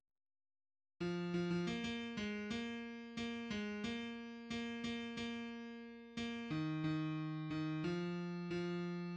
{ \clef bass \tempo 4=90 \key des \major \time 2/4 \set Score.currentBarNumber = #1 \bar "" r4 r8 f8 f16 f16 bes bes8 aes bes4 bes8 aes bes4 bes8 bes8 bes4. bes8 ees ees4 ees8 f4 f } \addlyrics {\set fontSize = #-2 doggy doogy } \midi{}